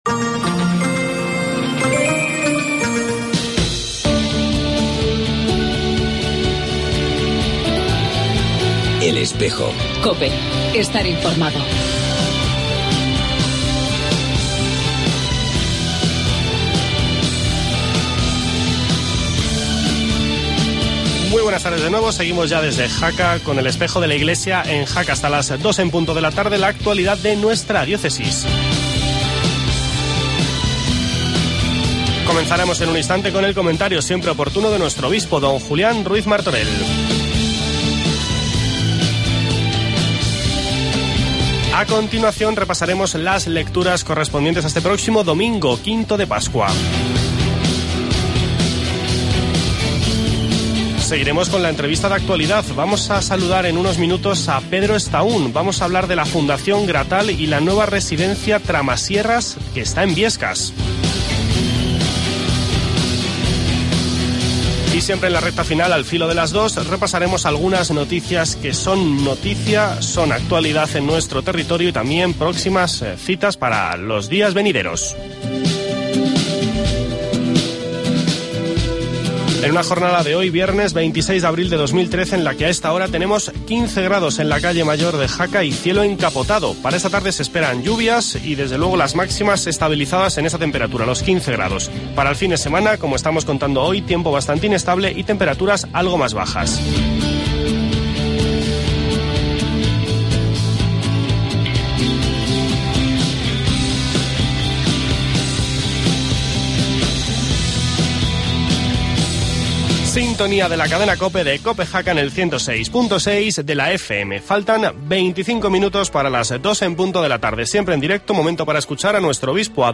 Escuchamos el comentario de don Julián Ruiz Martorell, obispo de Jaca y Huesca